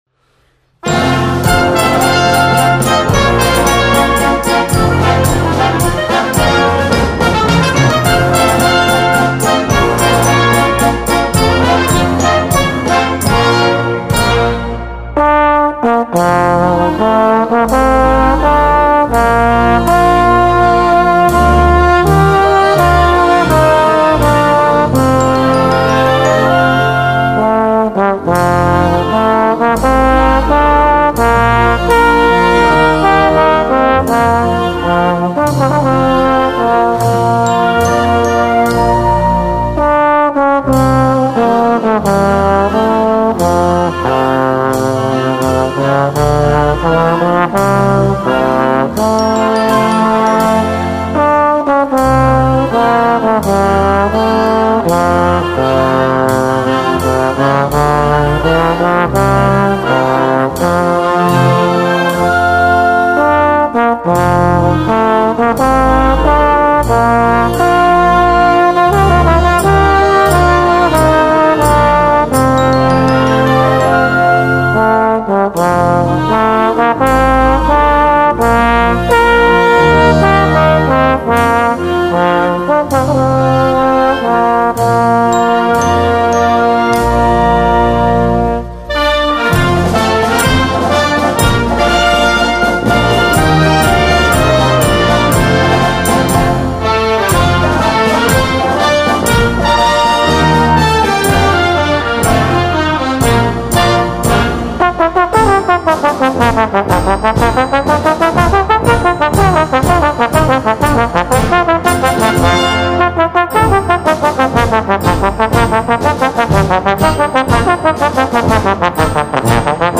Gattung: Solo mit Blasorchester
Besetzung: Blasorchester